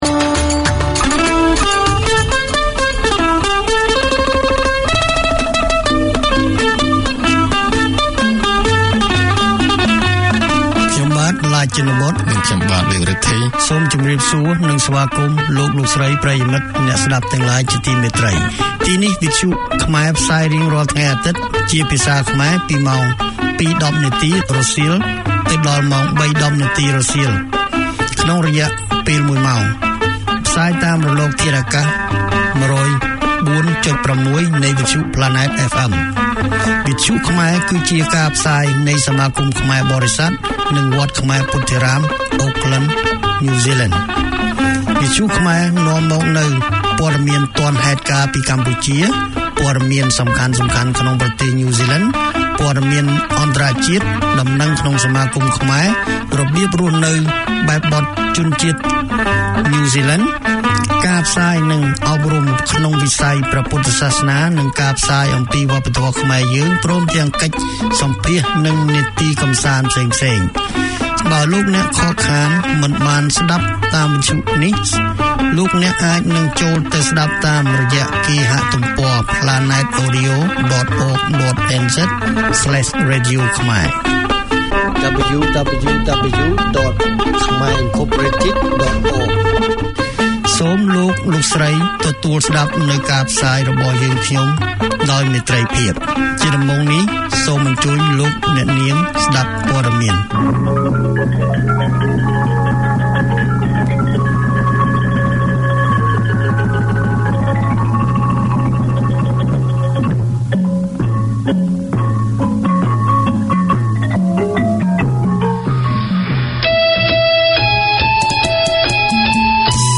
Radio made by over 100 Aucklanders addressing the diverse cultures and interests in 35 languages.